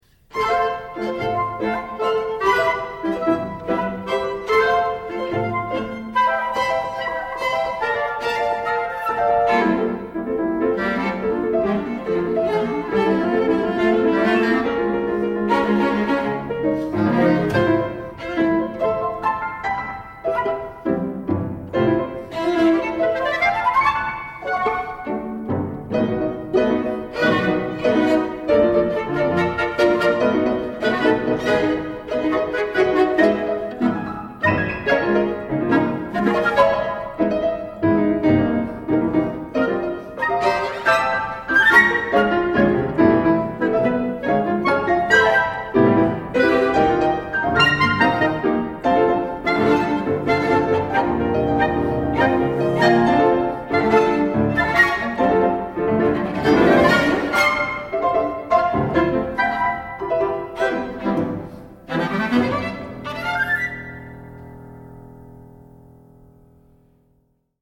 ” a brilliant, pointillistic instrumental interlude.